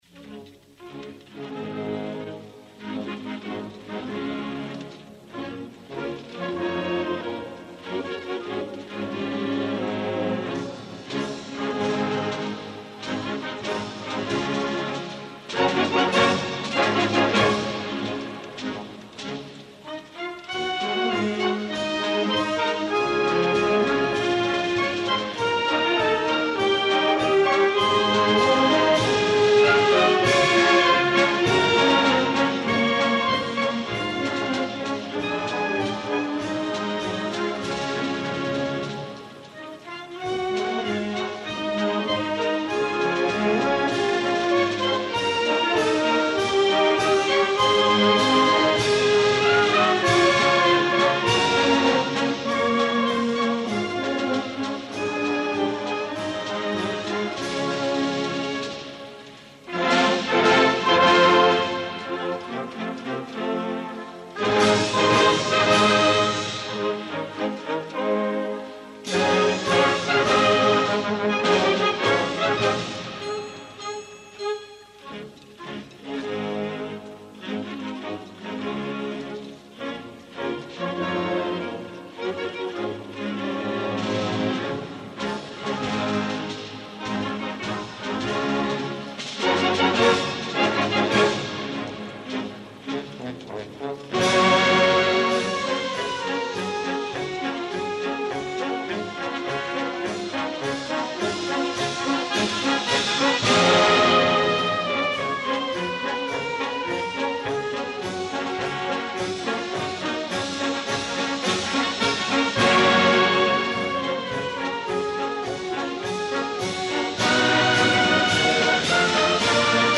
Concert extraordinari 1988. Esglesia parroquial de Porreres Nostra Senyora de la Consolació.